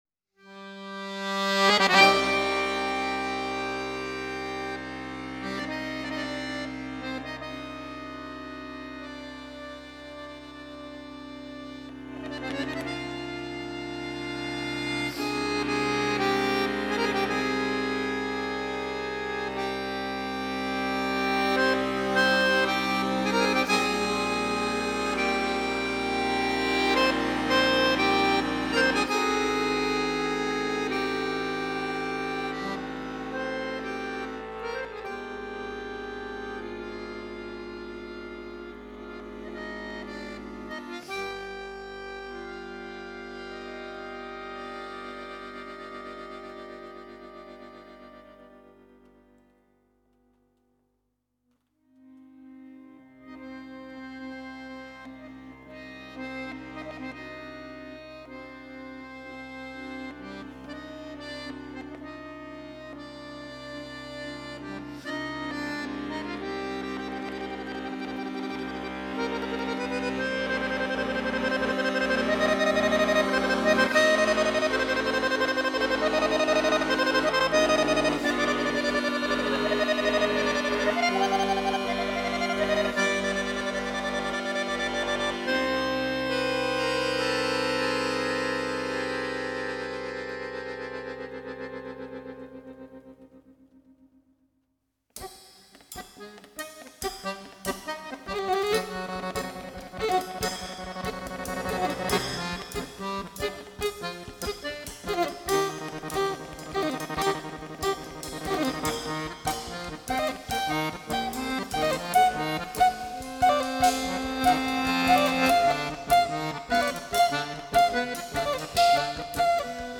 zanfonista
bajista
batería